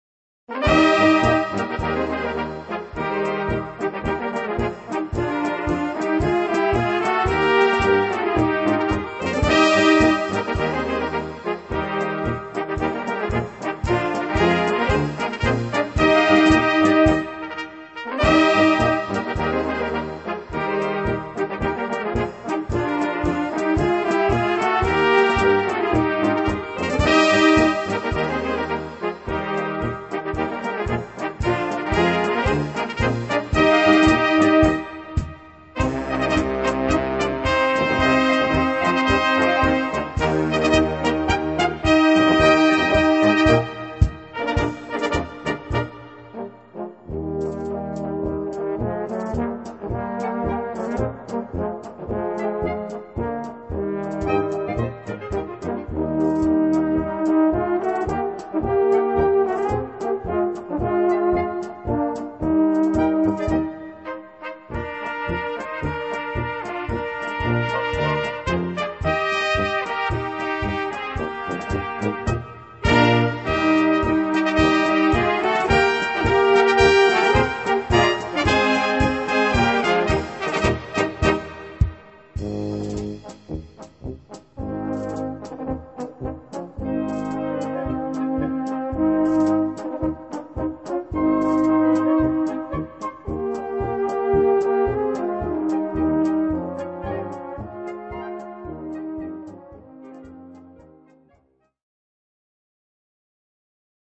Gattung: Marschpolka
Besetzung: Blasorchester